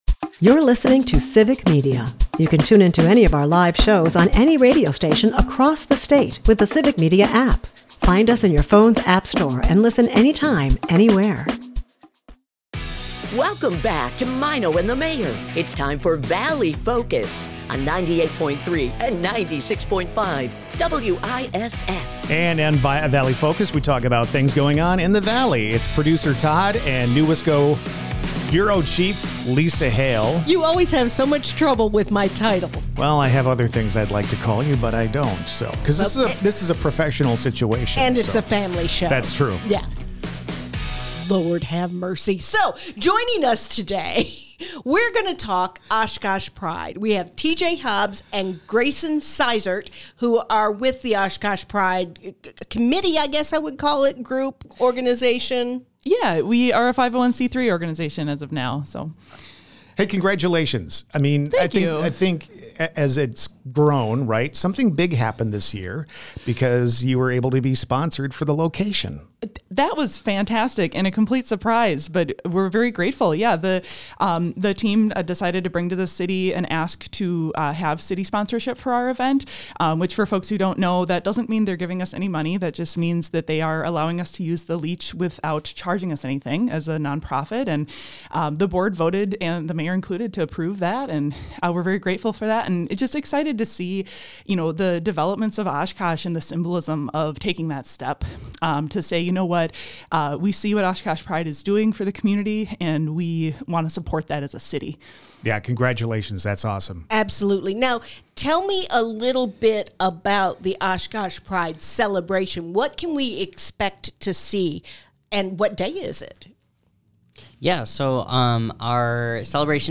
Valley Focus is a part of the Civic Media radio network and airs weekday mornings at 6:50 a.m. as part of the Maino and the Mayor Show on 96.5 and 98.3 WISS in Appleton and Oshkosh.